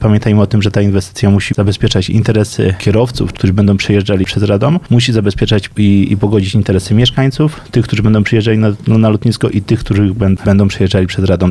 Jestem pełen obaw czy ta inwestycja ruszy, ale trzymam za nią kciuki, mówi Łukasz Podlewski, przewodniczący radnych PiS: